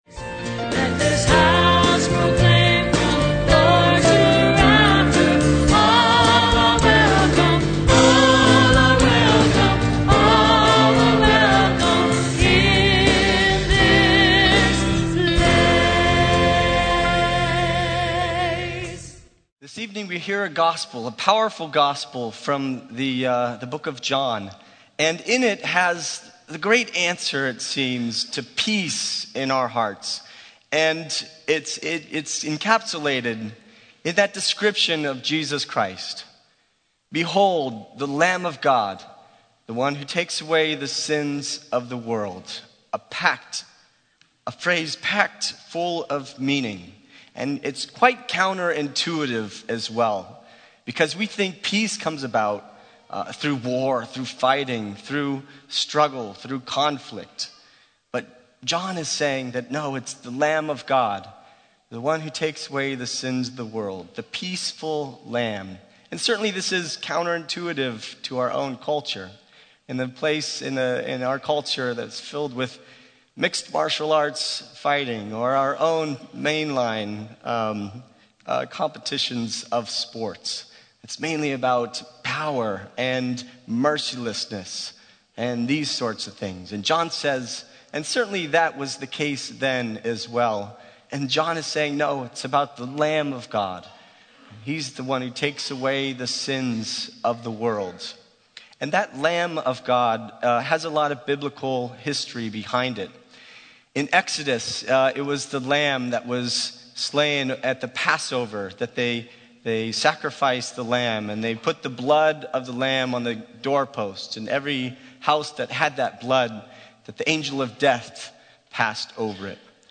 Homily - 1/16/11 - 2nd Sunday Ordinary Time